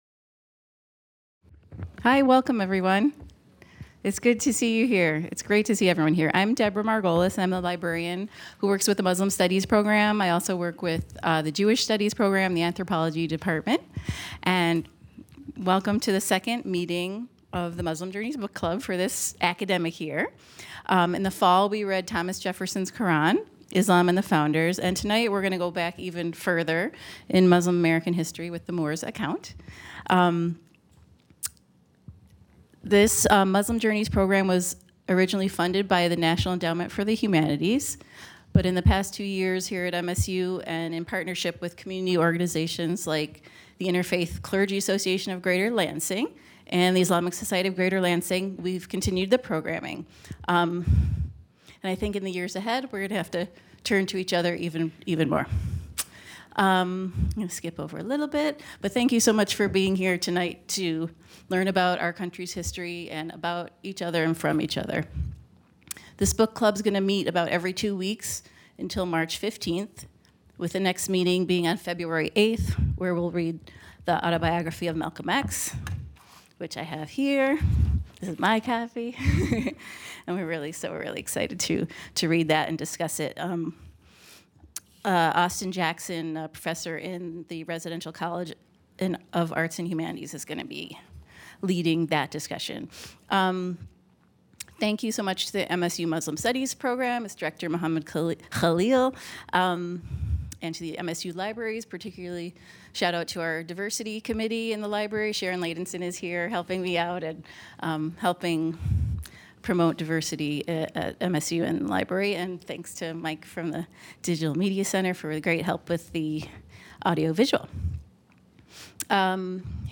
delivers a presentation on the book "The Moor's account"